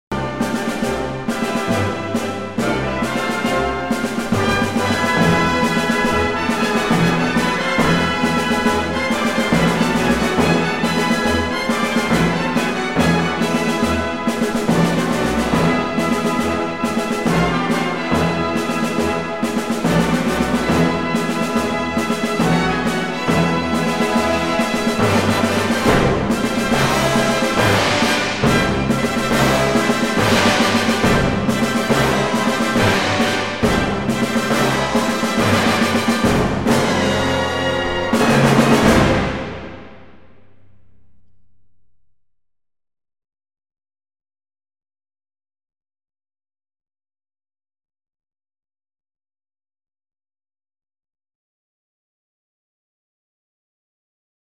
fortissimo.mp3